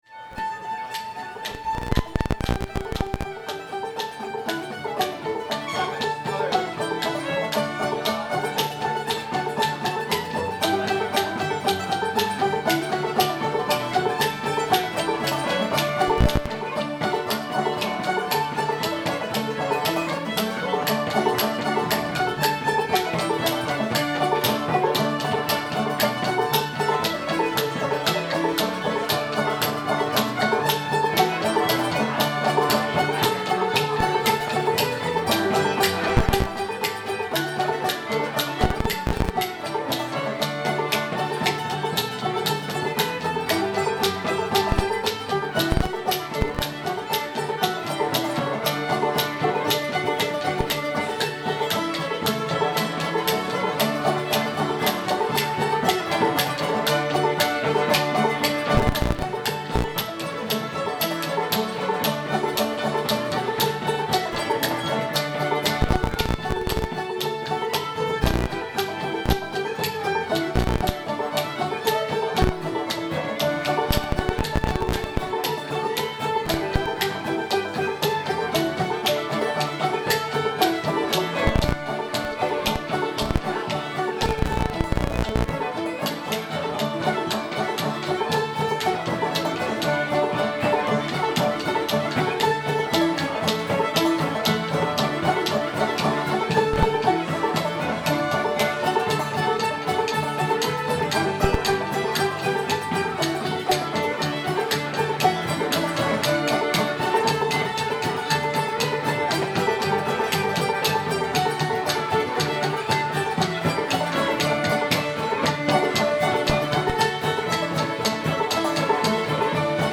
mississippi sawyer [D]